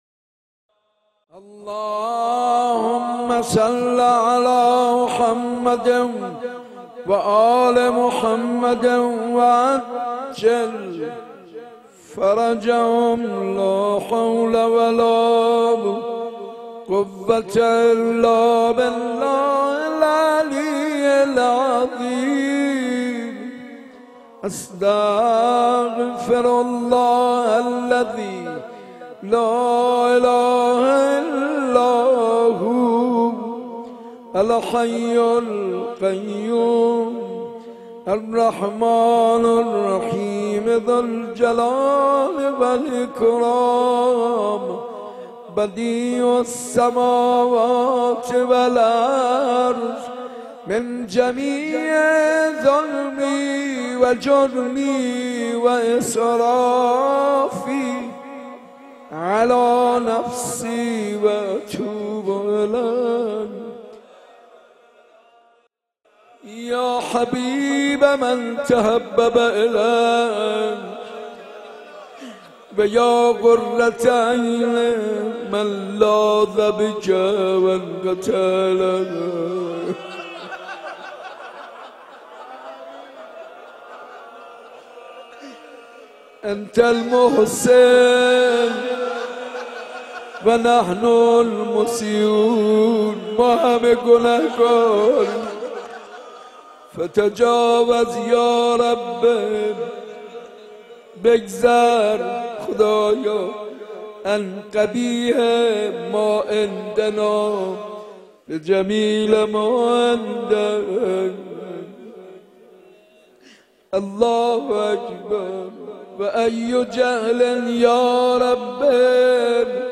مناجات خوانی